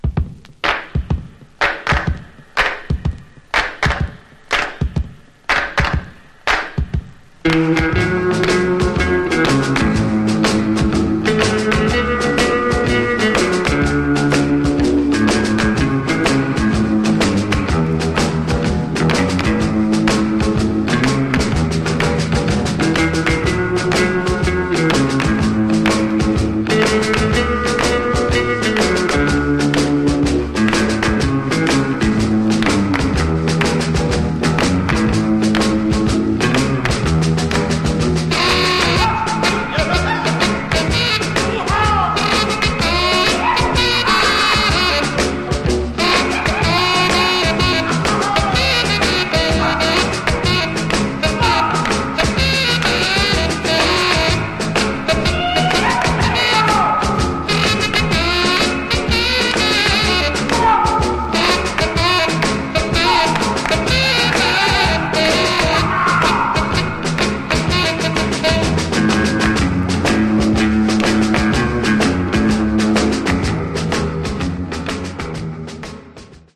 Genre: Instrumental Rock
This iconic instrumental rocker